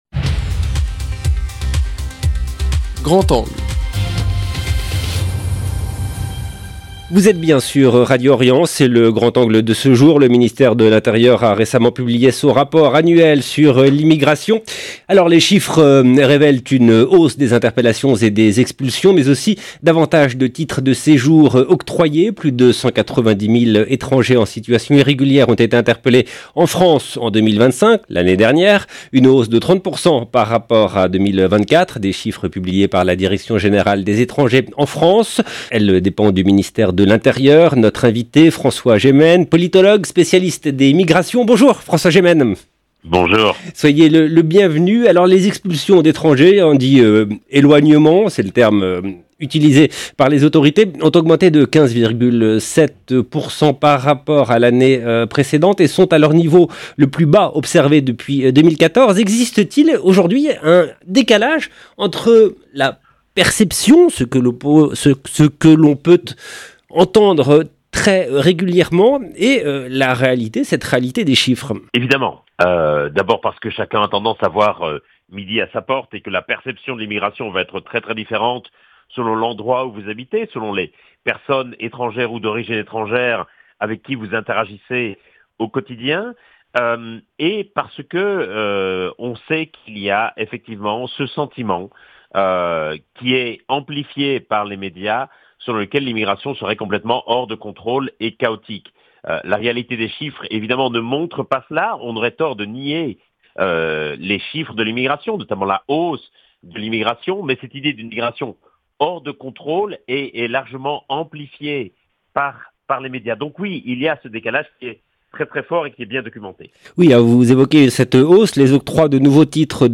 politologue spécialiste des migrations